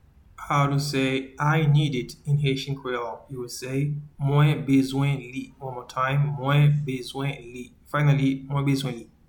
Pronunciation:
I-need-it-in-Haitian-Creole-Mwen-bezwen-li.mp3